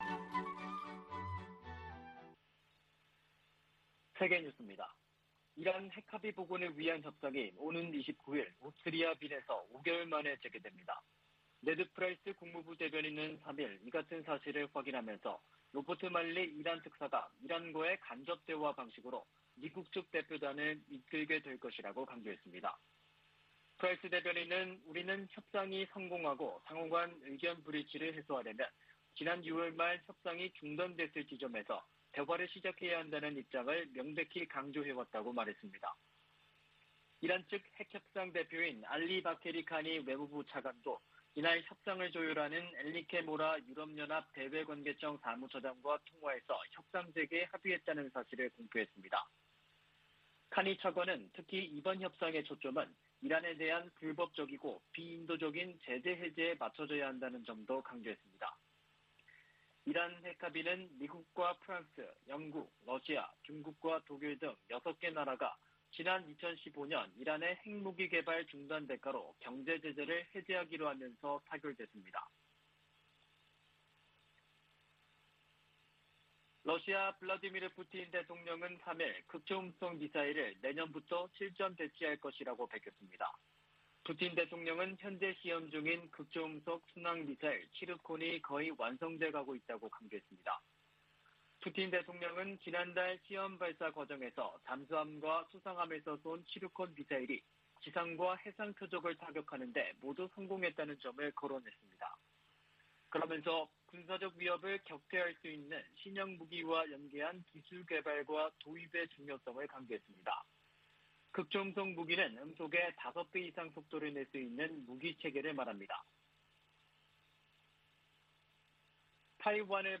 VOA 한국어 아침 뉴스 프로그램 '워싱턴 뉴스 광장' 2021년 11월 5일 방송입니다. 북한이 유엔총회에서 주한 유엔군사령부 해체를 다시 주장했습니다. 마크 밀리 미 합참의장은 북한 정부가 안정적이라며 우발적 사건이 발생하지 않을 것으로 내다봤습니다. 중국의 핵탄두가 2030년 1천개를 넘어설 수 있다고 미 국방부가 전망했습니다.